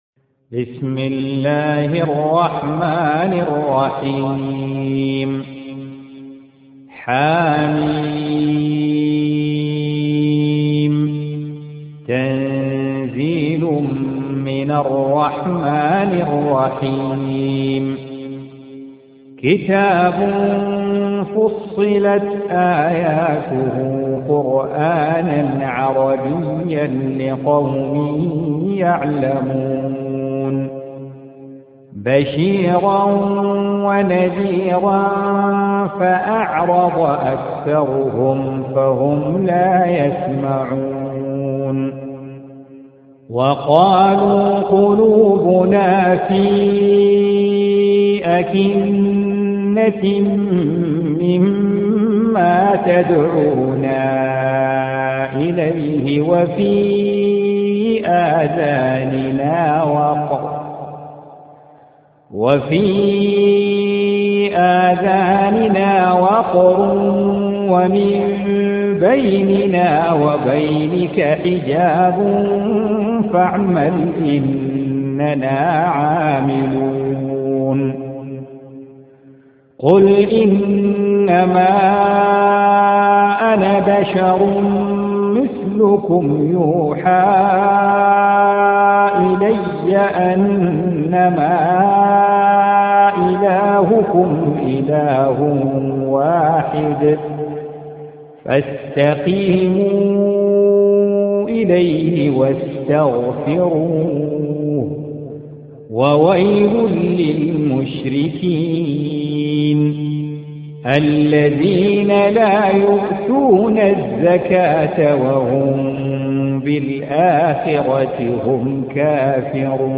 Surah Repeating تكرار السورة Download Surah حمّل السورة Reciting Murattalah Audio for 41. Surah Fussilat سورة فصّلت N.B *Surah Includes Al-Basmalah Reciters Sequents تتابع التلاوات Reciters Repeats تكرار التلاوات